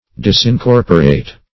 disincorporate - definition of disincorporate - synonyms, pronunciation, spelling from Free Dictionary
Disincorporate \Dis`in*cor"po*rate\, a.